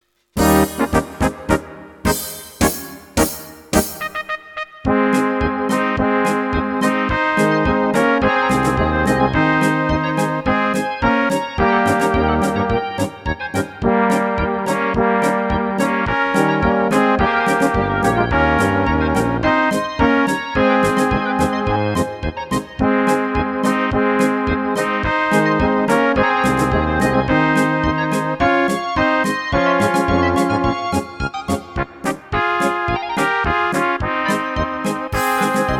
Rubrika: Národní, lidové, dechovka
- polka
Karaoke